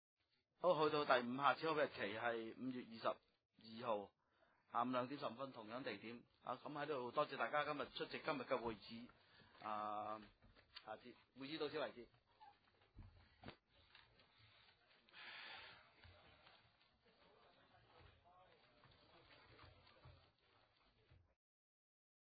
深水埗区议会会议室